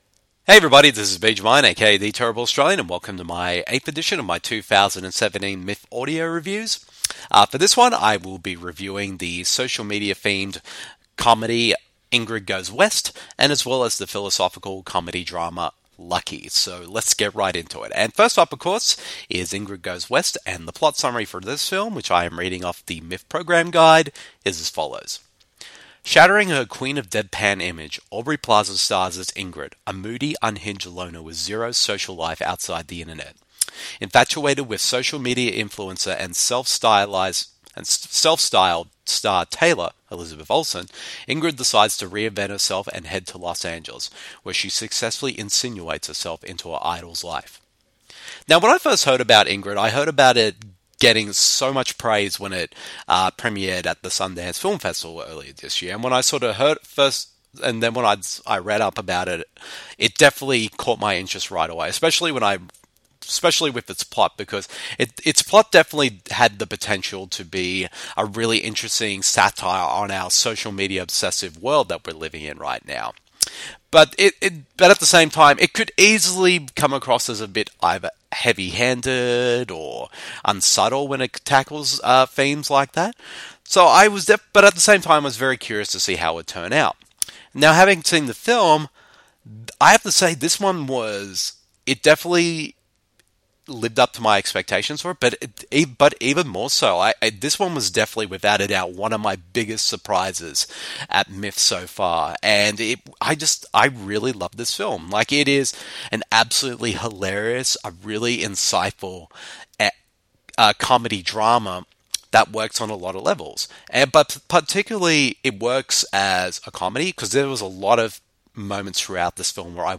Audio reviews